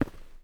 mining sounds
ROCK.1.wav